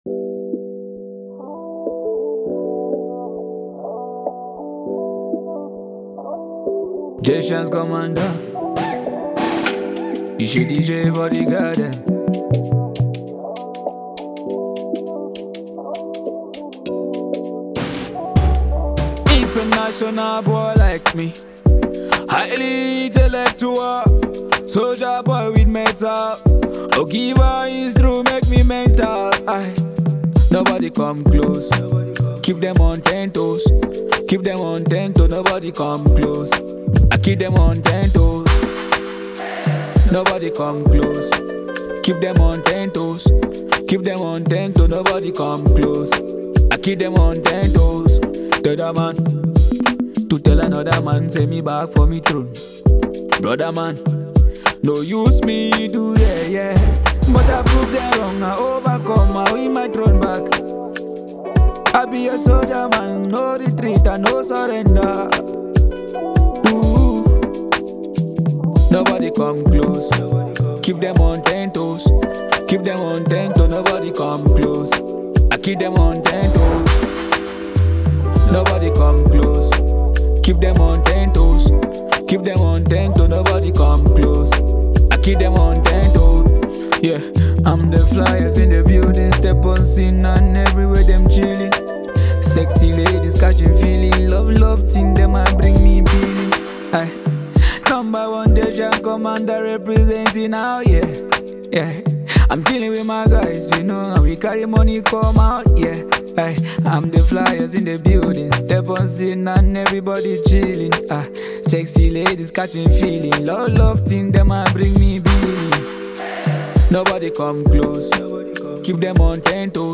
Quite a calm and mood-soothing jam to keep your day going…